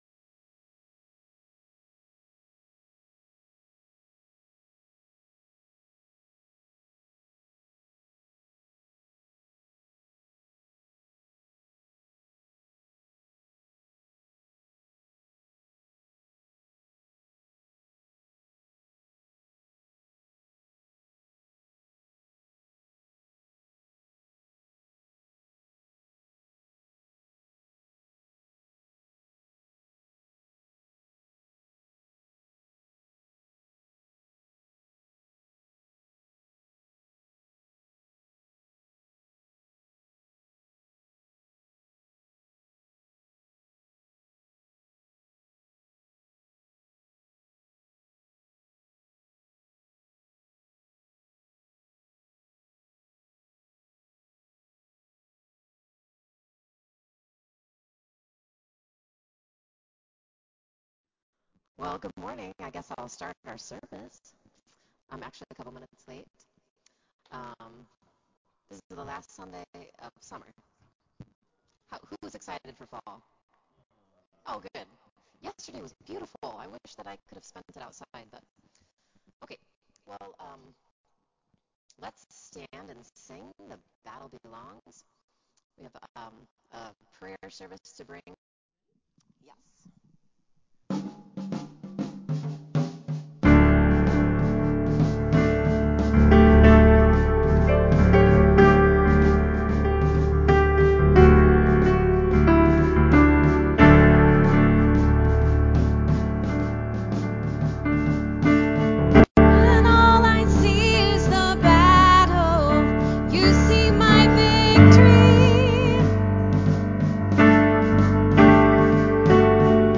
I apologize for the audio at the start of the video.